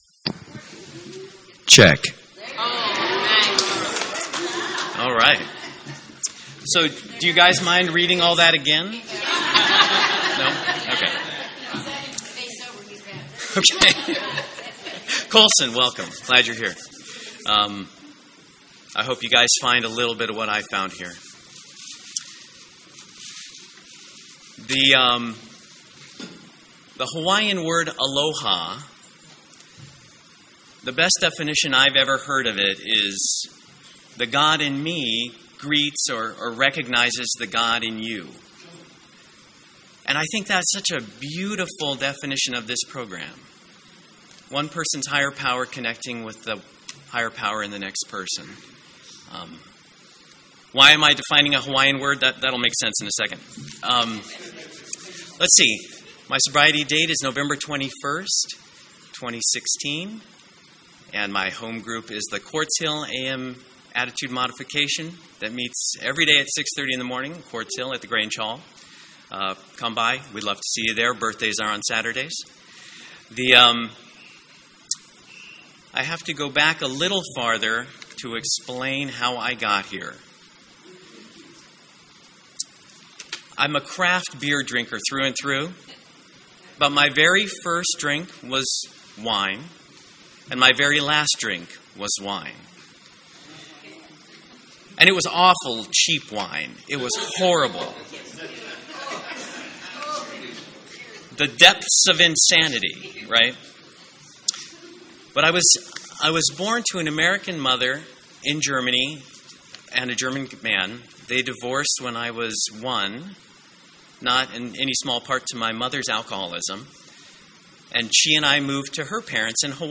47th Annual San Fernando Valley AA Convention